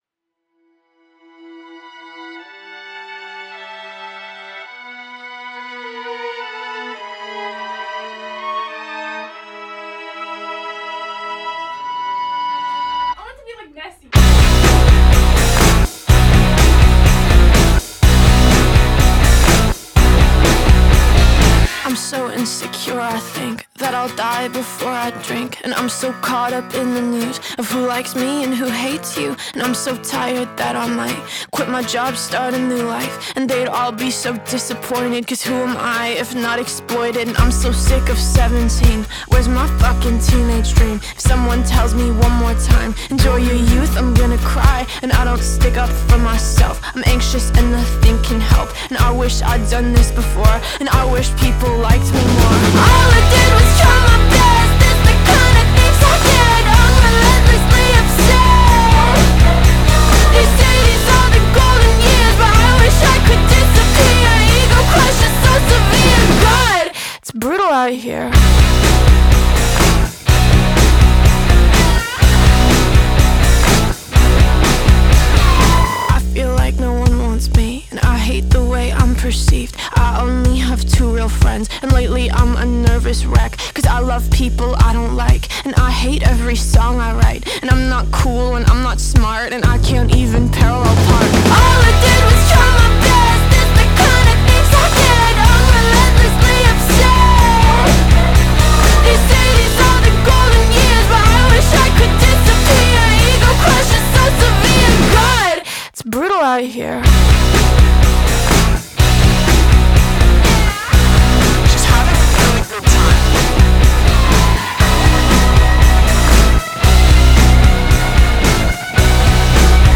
Genre: Punk- rock